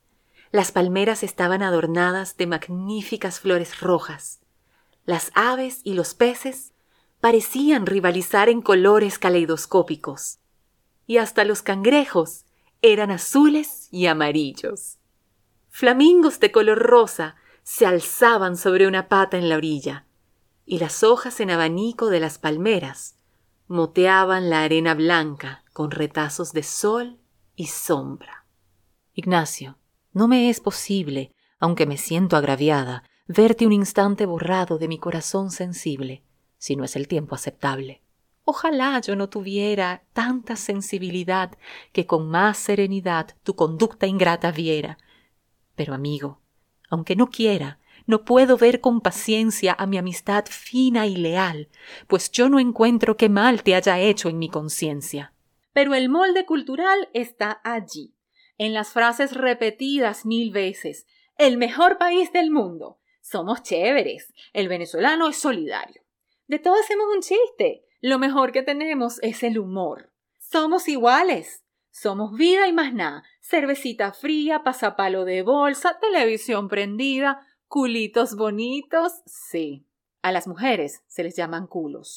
Female
Audiobooks
Non-Fiction, Spanish, Reel
0630Spanish_Audiobook_Reel.mp3